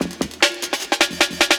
61 LOOP03.wav